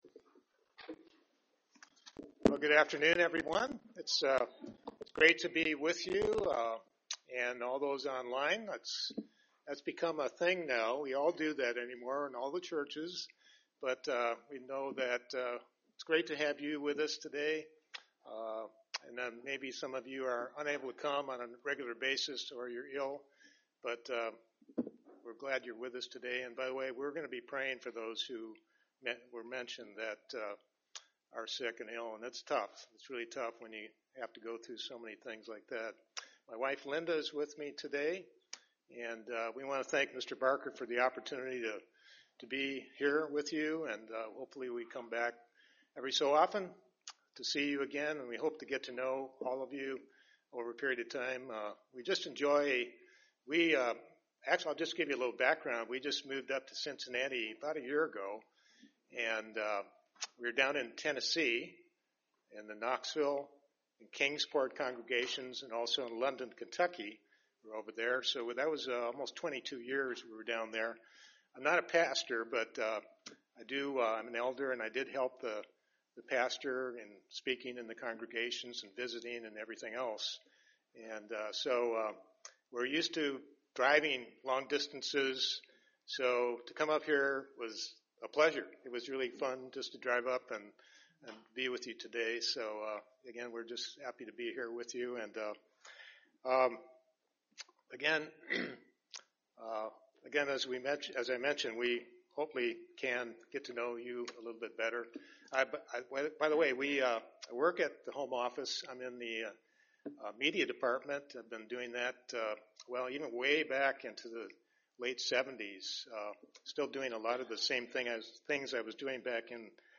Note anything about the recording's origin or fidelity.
Given in Dayton, OH